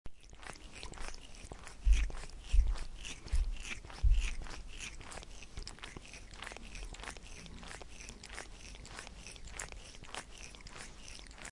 Catlicking Bouton sonore
Play and download the Catlicking sound effect buttons instantly!